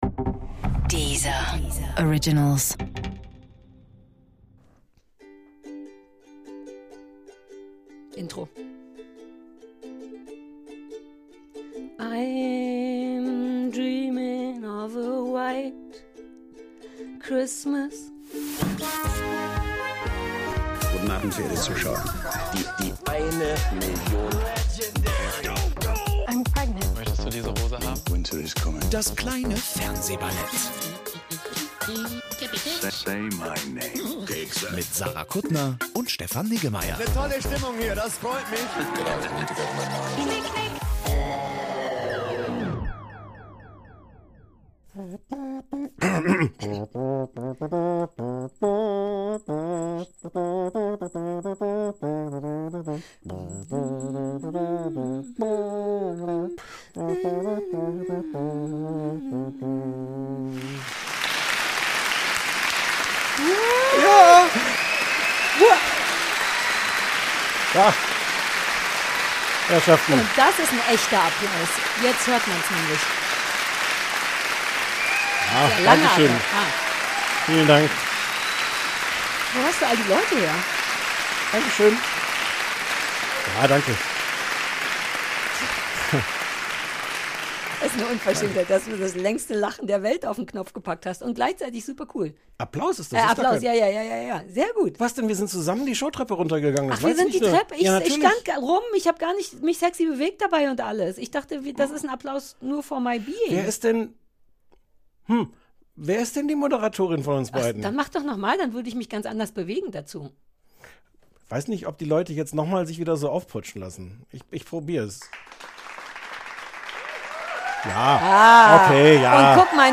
In einer großen Gala mit Eurovisionsfanfare und Applaus und allem feiern wir 10 Staffeln und 5 Jahre Fernsehballett! Wir hören alte Ausschnitte, verraten Geheimnisse, arbeiten die Sachen mit Jürgen von der Lippe und Rea Garvey auf und lassen es nochmal richtig knistern zwischen uns.